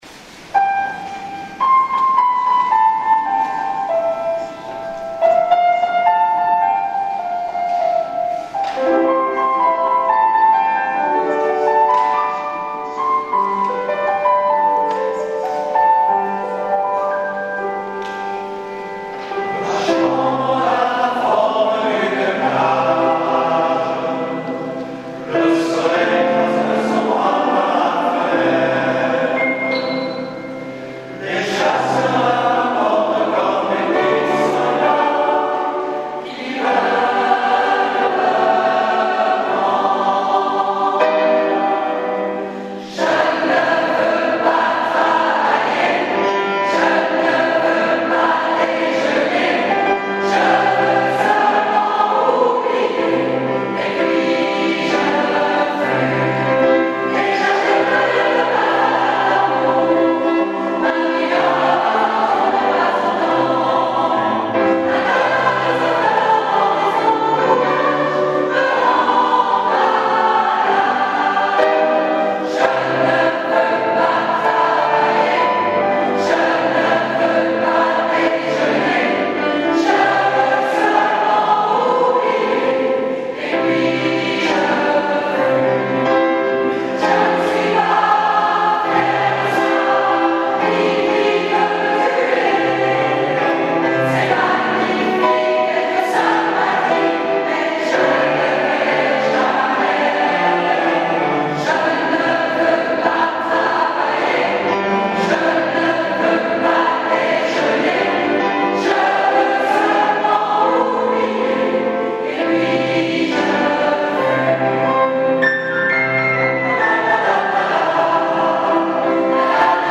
Avertissement : Ce sont des enregistrements amateurs « live », preneurs de son inconnus… Aucune prétention commerciale bien entendu !
C’est au concert, en public et non en studio, que la musique vit et existe.